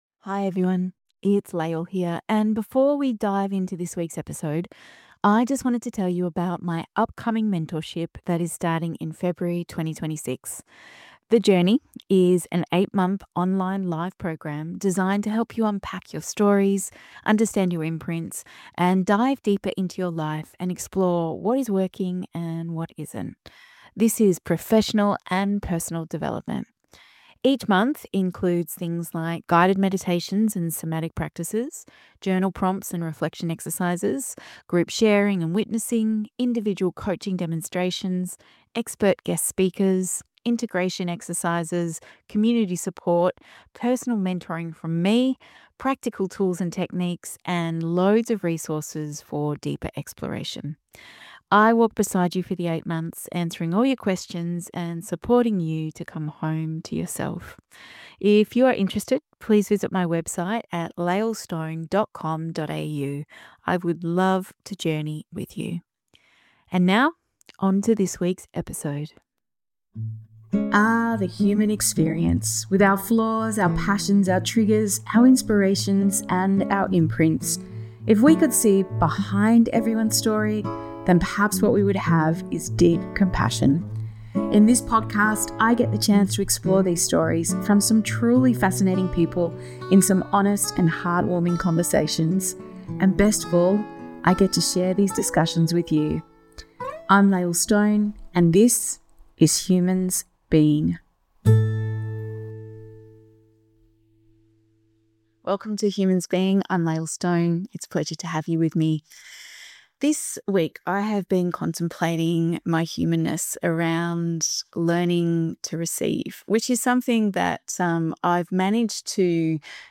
In this raw and moving conversation